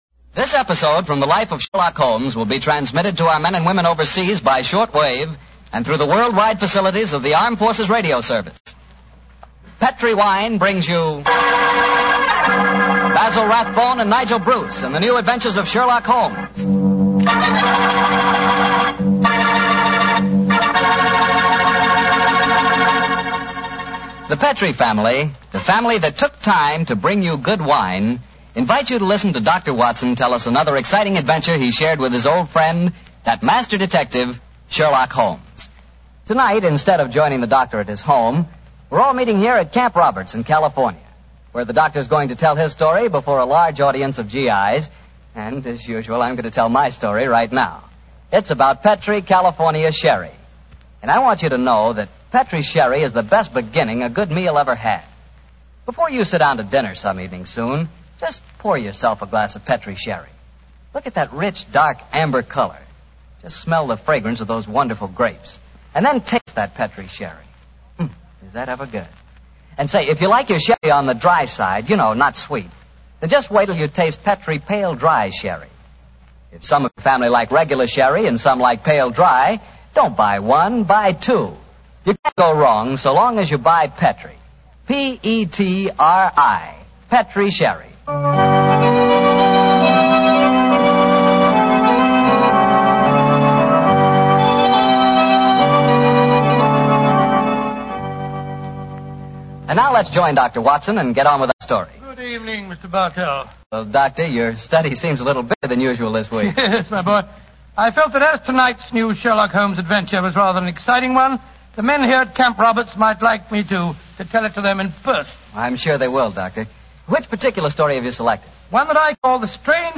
Radio Show Drama with Sherlock Holmes - The Murder In Wax 1946